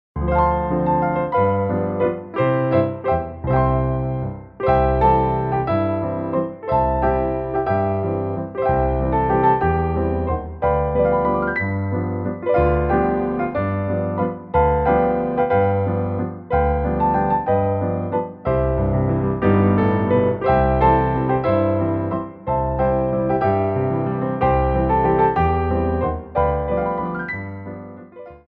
Grand Allegro
3/4 (16x8)